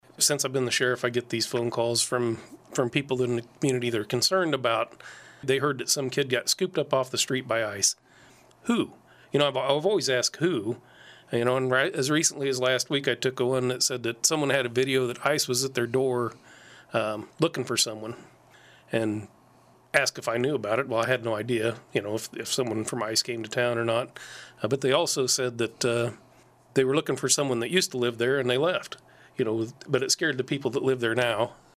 Saline County Sheriff Roger Soldan joined in on the KSAL Morning News Extra with a look at how local law enforcement works with U.S. Immigration and Customs Enforcement (ICE).